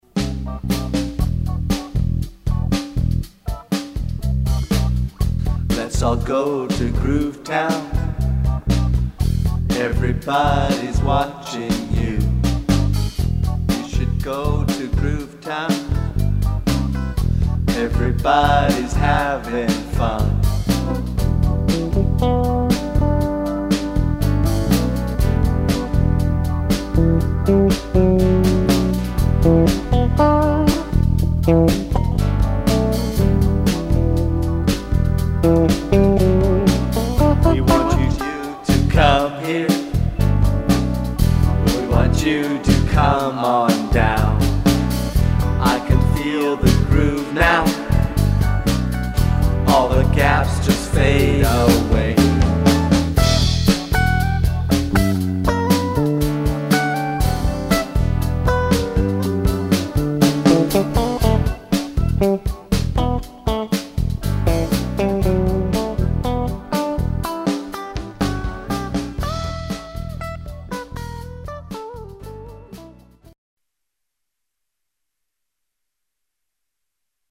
lead guitar
bass\drums\keys\rhythm guitar\vox